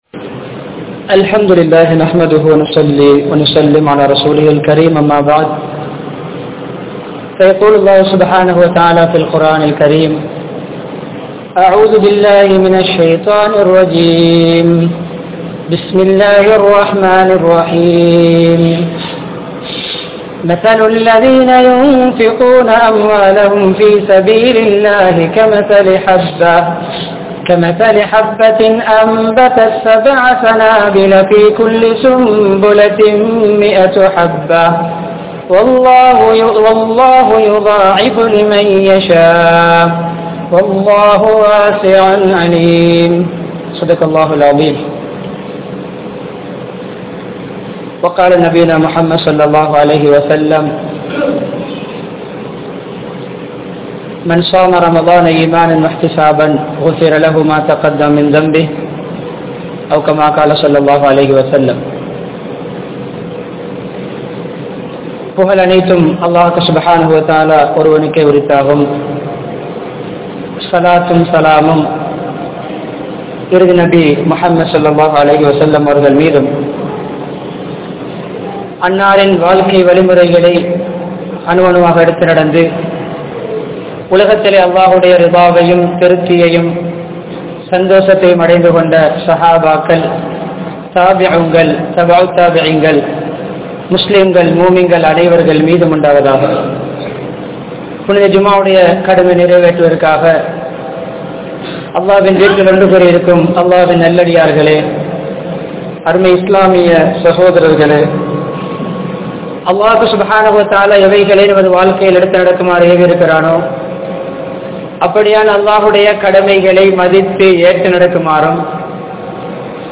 Ramalaanin Sirappai Peanuvoam (ரமழானின் சிறப்பை பேனுவோம்) | Audio Bayans | All Ceylon Muslim Youth Community | Addalaichenai
Muhiyadeen Jumua Masjidh